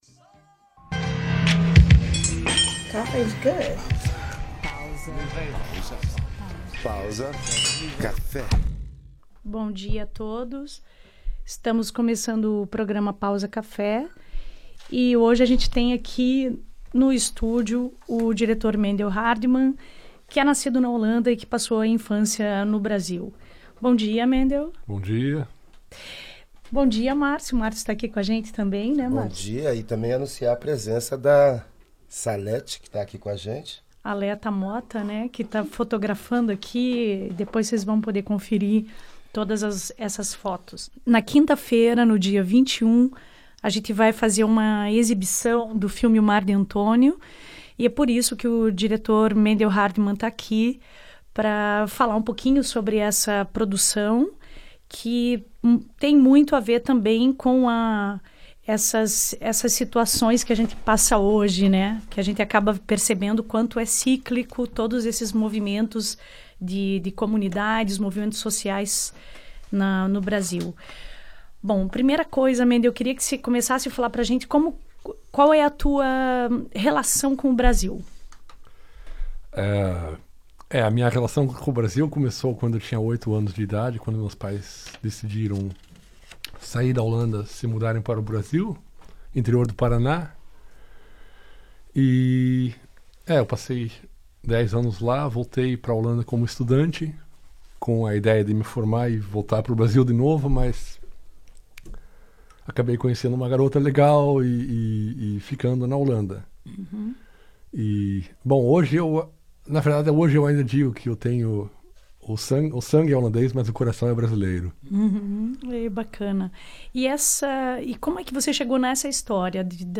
Entrevista no programa Pausa Café